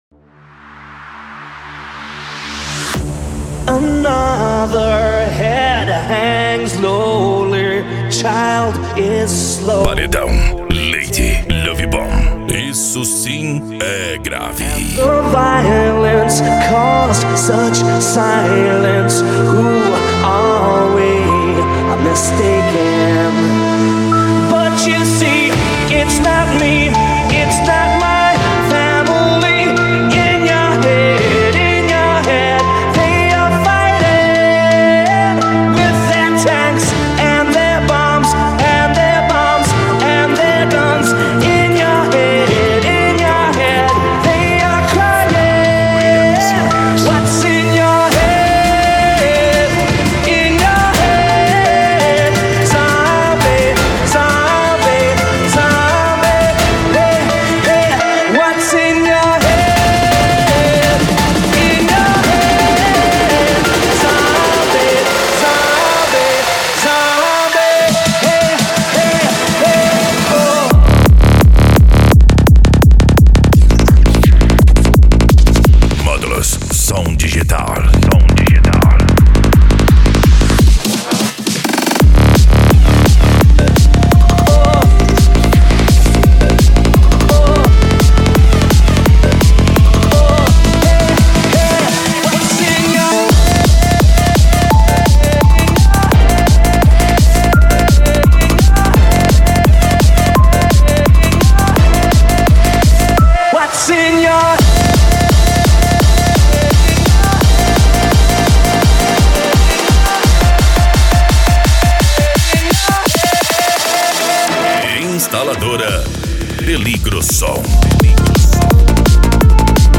Bass
Deep House
Eletronica
Psy Trance